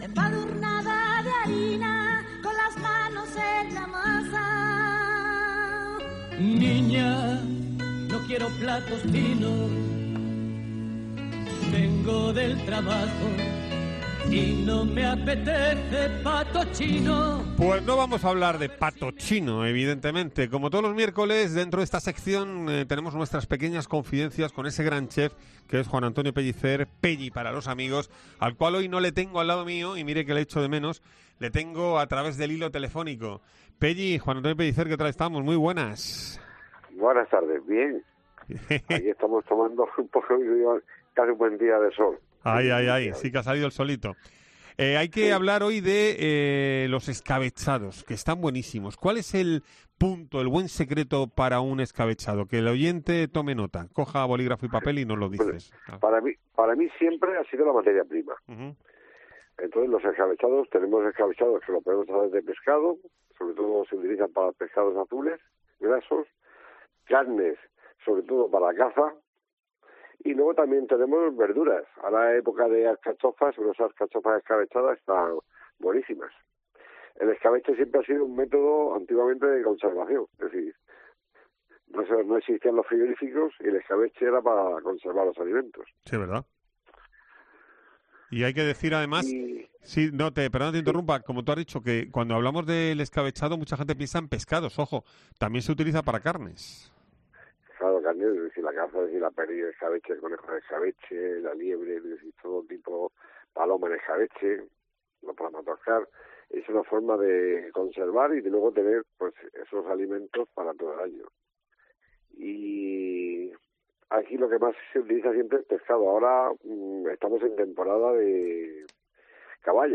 La entrevista completa en COPE Murcia